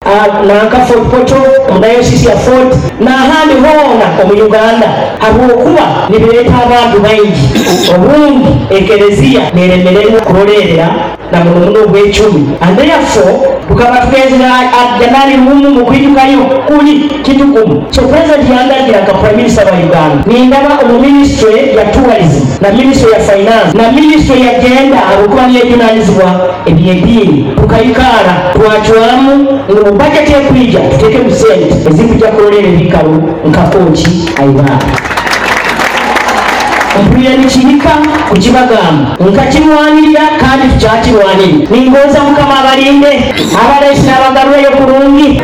While speaking at a pilgrimage event in Kooki Haibaale at the birthplace of St. Andrea Kaahwa, one of the Ugandan Martyrs […]